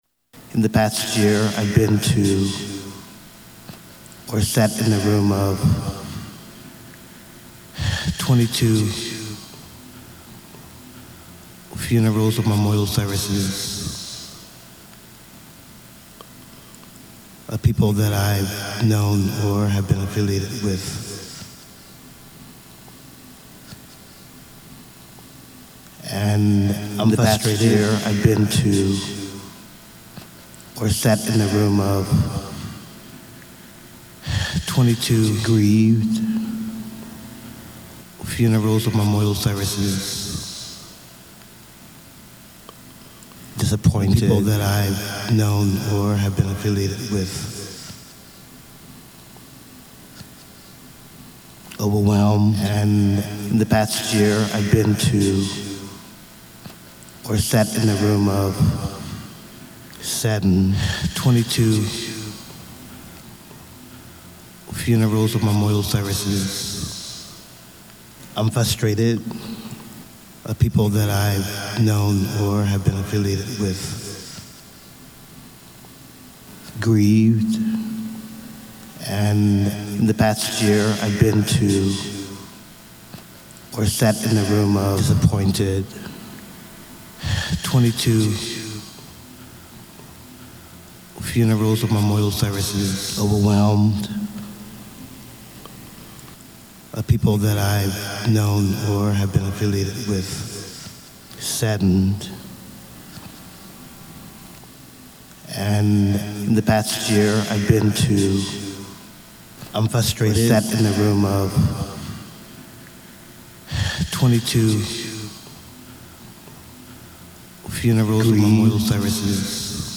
Andy Warhol Museum, Wednesday, 30 November 2005